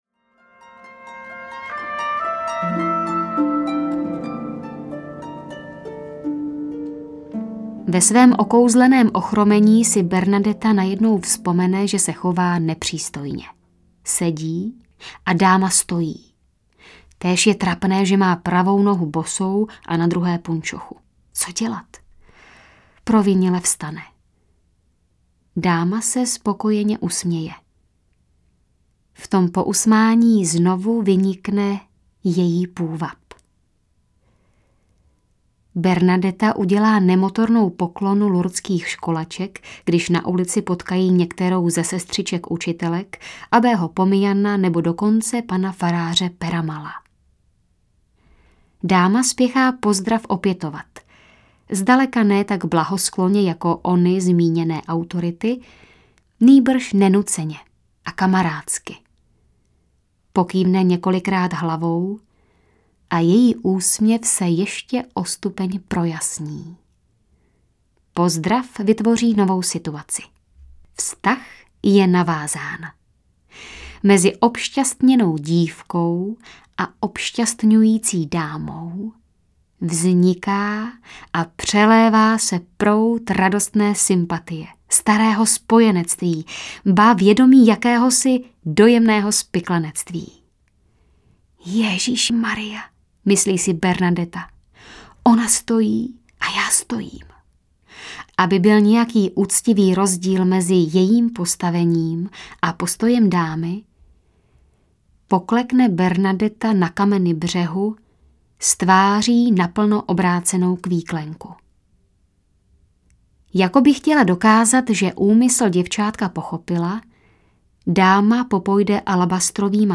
Píseň o Bernadettě audiokniha
Ukázka z knihy